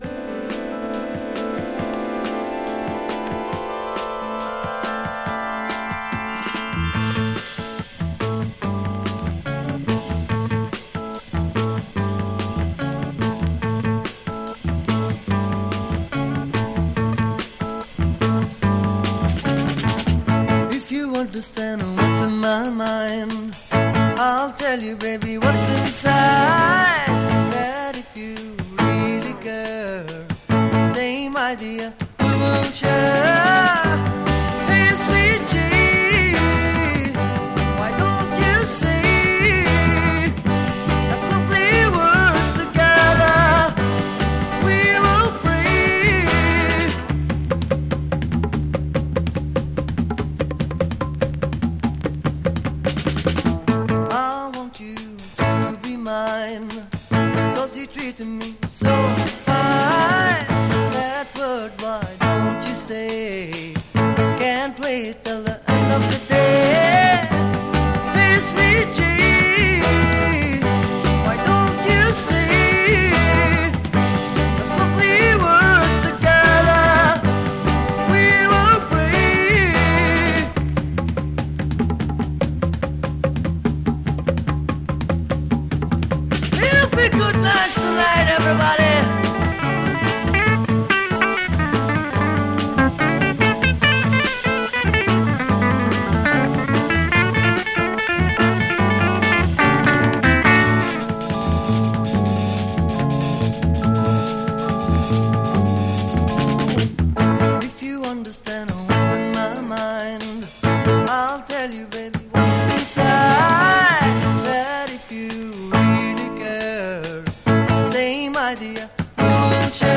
a psychedelic bouncy Surf Mod Soul Funk track from Lebanon
the Lebanese drummer, keyboardist, guitarist and trumpeter